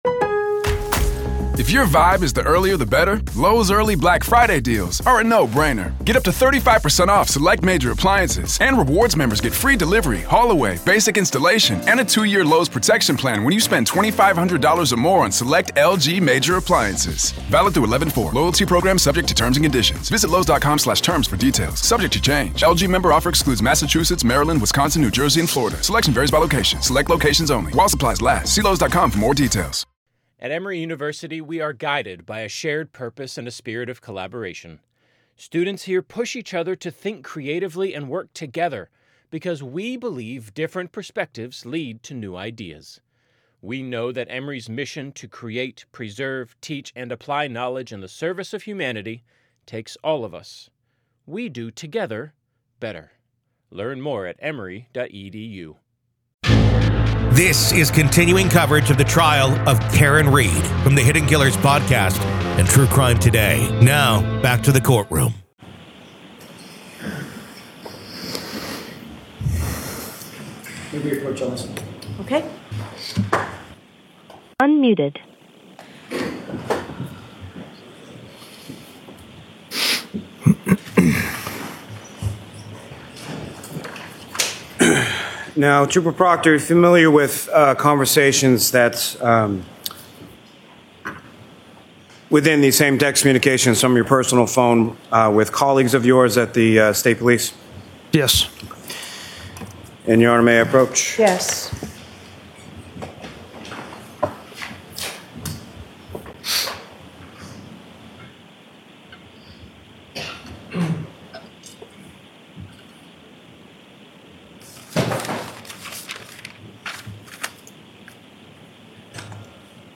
SOME LANGUAGE MAY BE OFFENSIVE:
Headliner Embed Embed code See more options Share Facebook X Subscribe SOME LANGUAGE MAY BE OFFENSIVE: Welcome to a special episode of "The Trial of Karen Read," where today, we find ourselves inside the courtroom of the case against Karen Read.